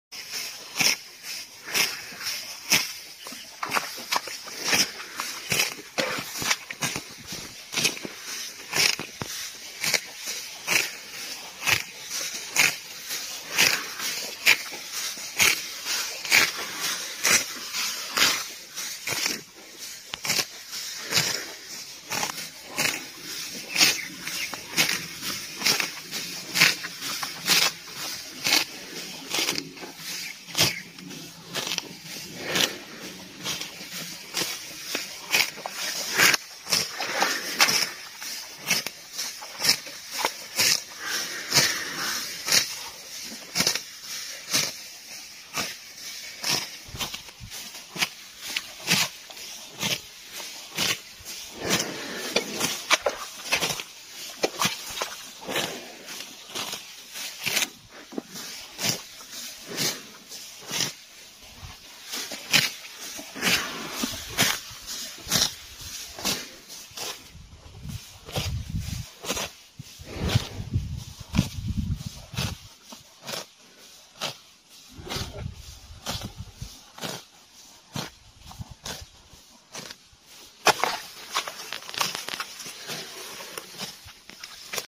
Satisfying ASMR: Cow's Crisp Chewing sound effects free download
Satisfying ASMR: Cow's Crisp Chewing in Natural Valley